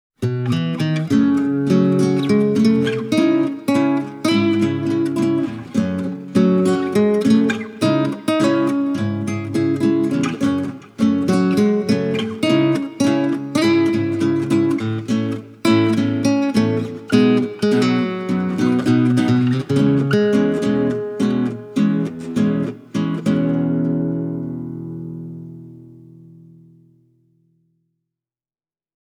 Tässä on kyse herkästi resonoivasta, isoäänisestä soittimesta, jossa myös työnjälki on kiitettävällä tasolla.
Kompensoidun tallaluun ansiosta Model A soi erittäin puhtaasti koko otelaudassa.
Tässä kitarassa on kyllä niin viehättävän selkeä ja laulava ääni, että on jo melkein hankala lopettaa soittamista.